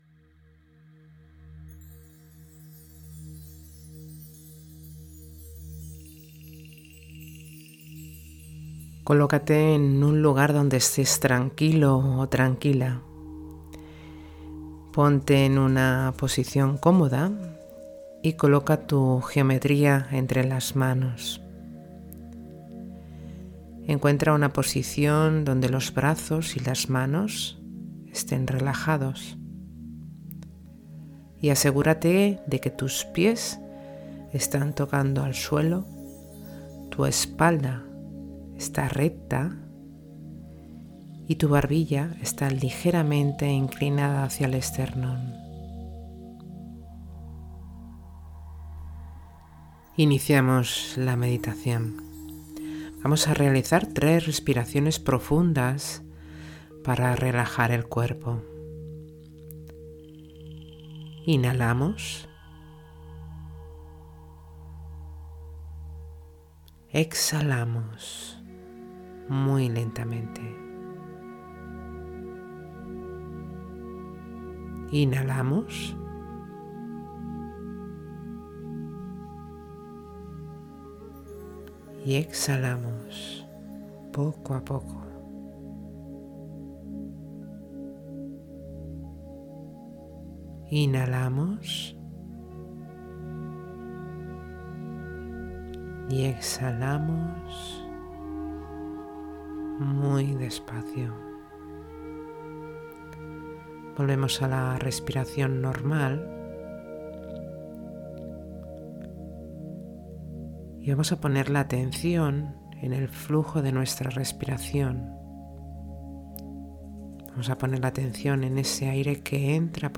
Meditación Básica Rombicosidodecaedro